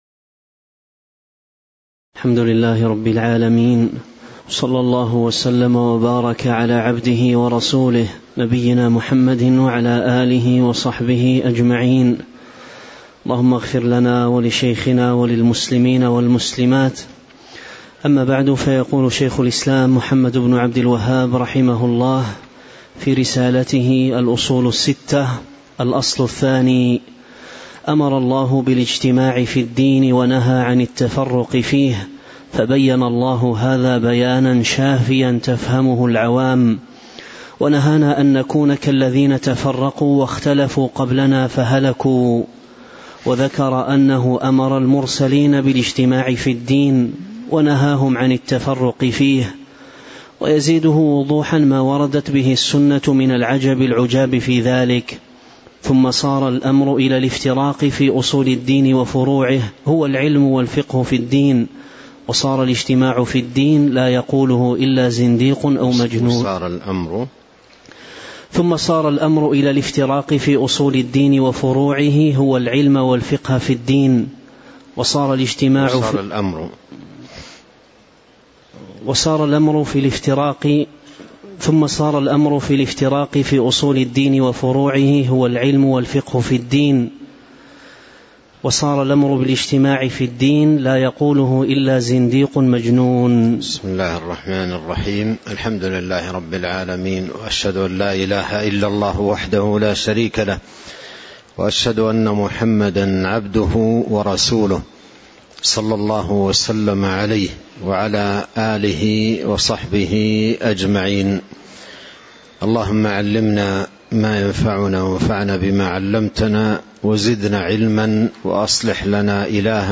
تاريخ النشر ٢٦ صفر ١٤٤٥ هـ المكان: المسجد النبوي الشيخ